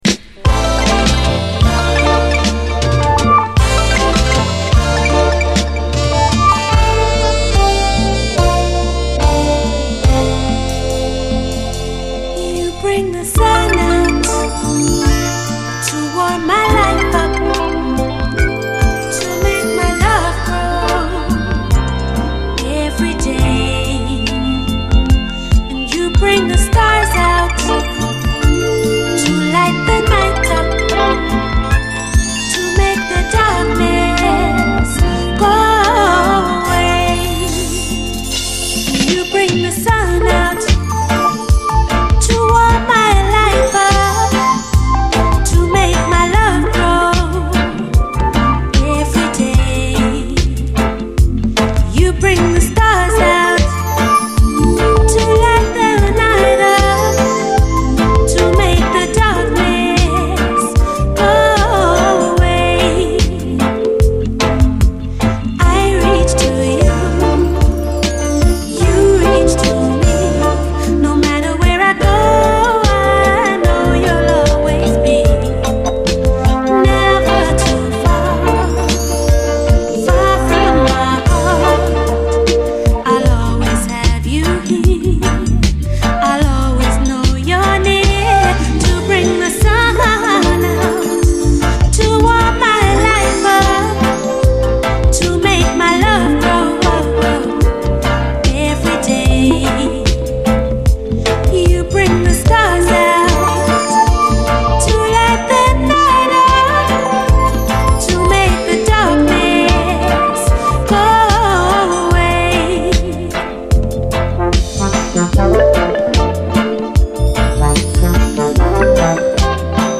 REGGAE
ホワホワしたプロダクションが夢心地です。